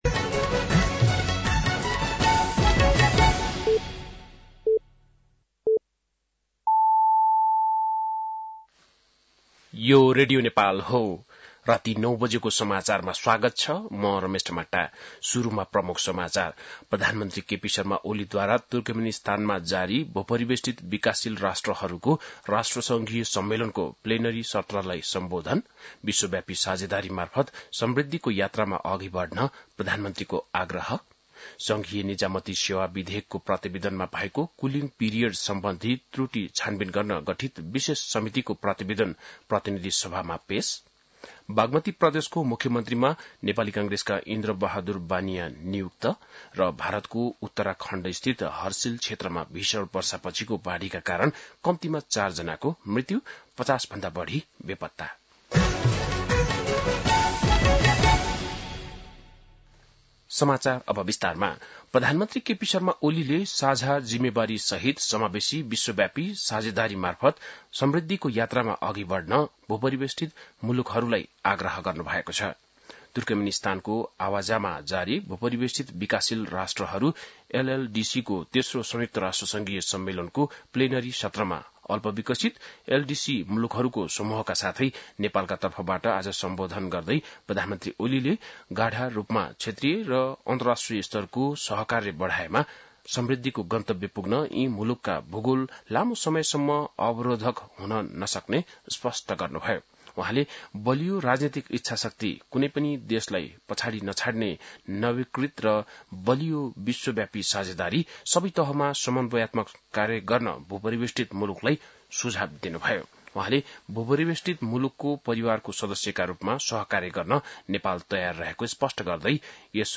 बेलुकी ९ बजेको नेपाली समाचार : २० साउन , २०८२